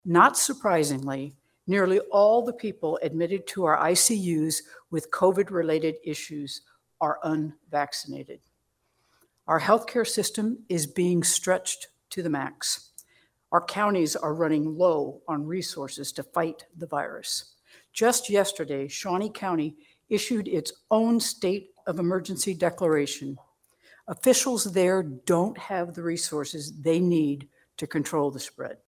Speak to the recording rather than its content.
During a special news conference, Friday morning from Saint Lukes Hospital in Kansas City the governor addressed a number of topics from hospital and ICU capacity in the state, to misinformation on the pandemic and the potential for spread of the virus in Kansas schools.